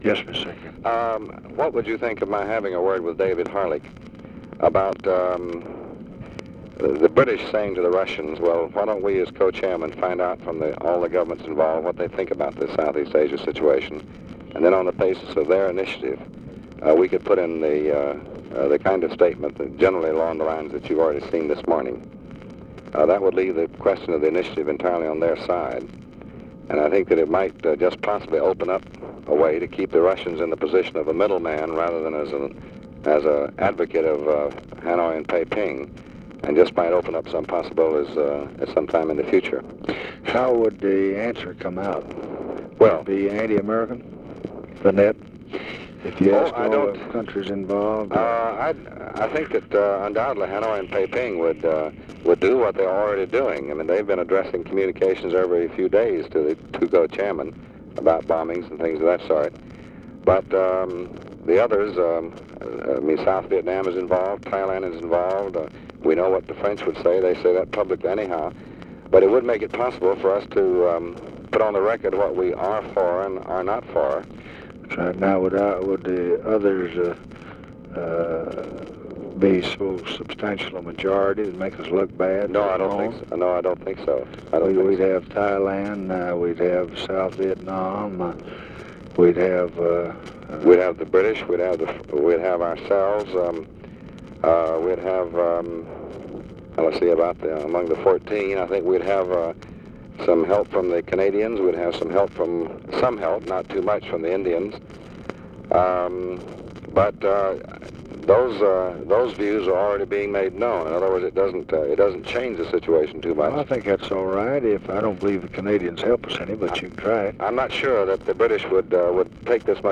Conversation with DEAN RUSK, February 18, 1965
Secret White House Tapes